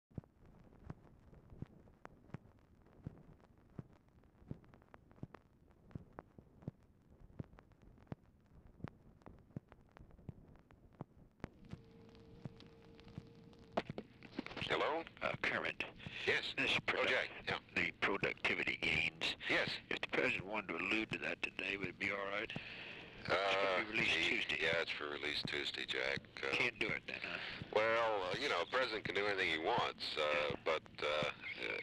RECORDING IS INTERRUPTED BY RECORDING OF FOLLOWING RECORDING
Format Dictation belt
Specific Item Type Telephone conversation